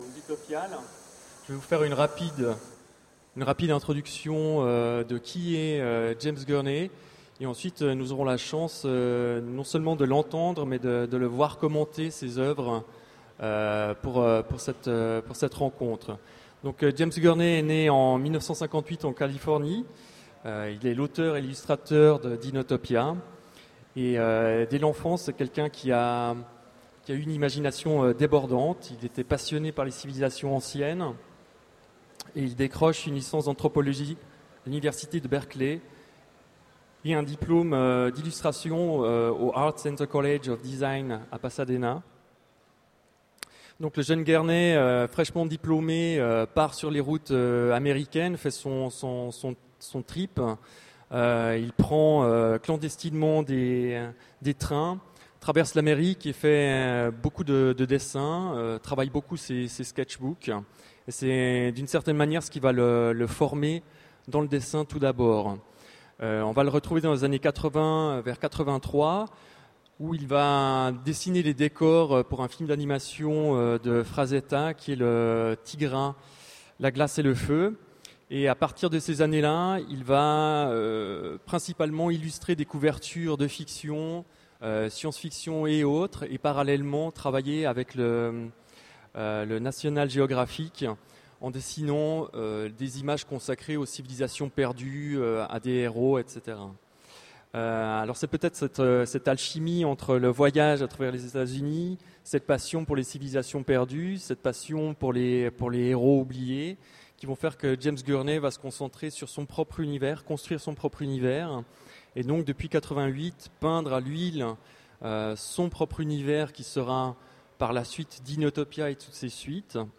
Voici l'enregistrement de la rencontre avec James Gurney aux Utopiales 2009.